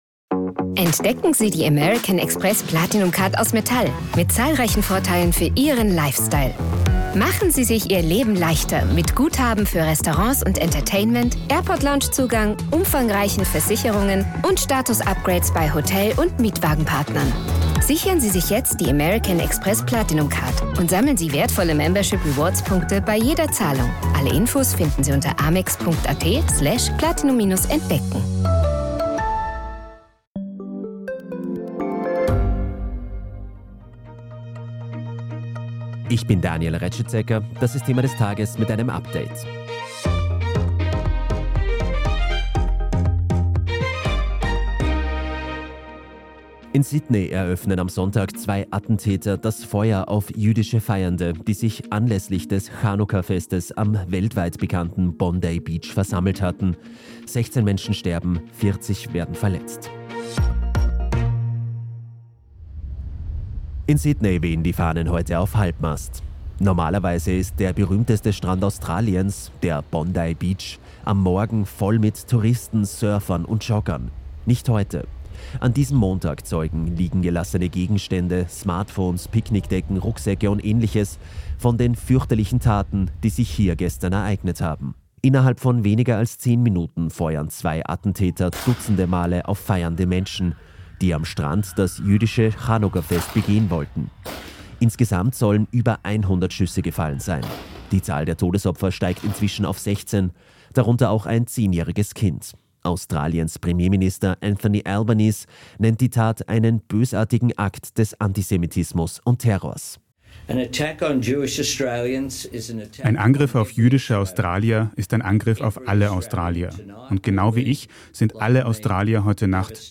Der Nachrichten-Podcast vom STANDARD